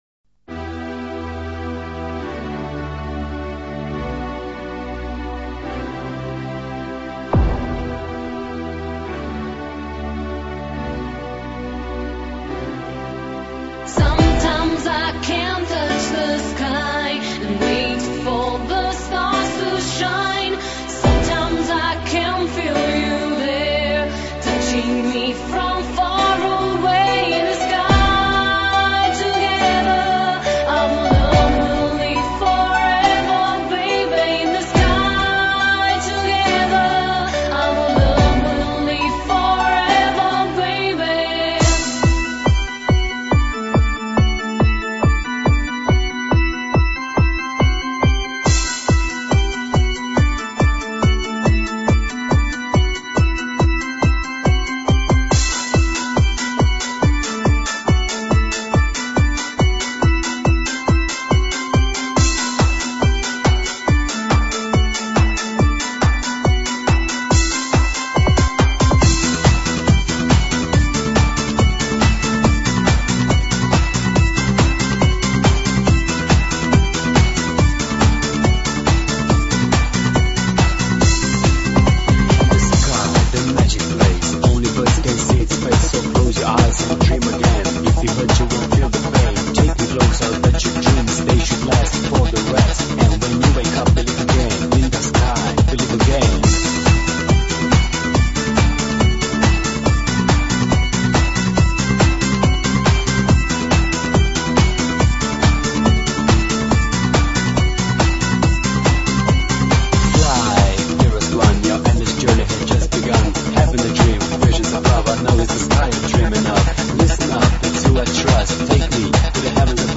Назад в Exclusive EuroDance 90-х